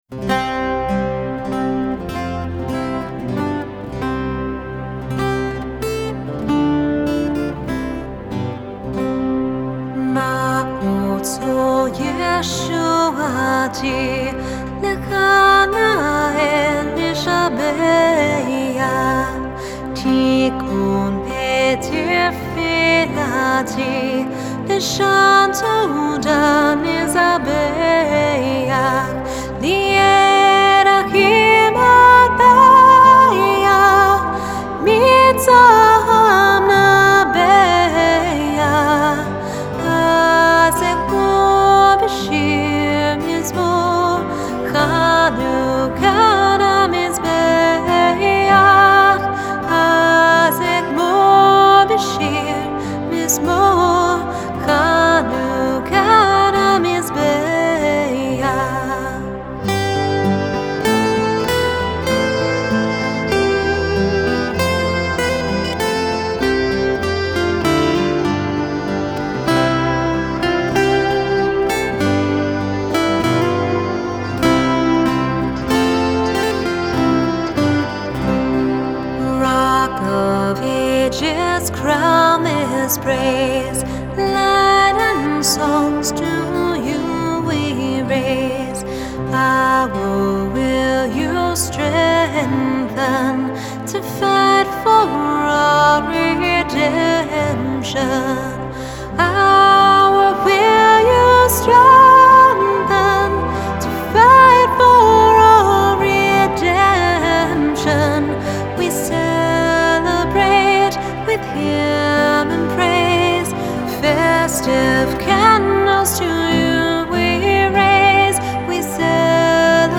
Жанр: фолк-рок
Genre: Folk, Rock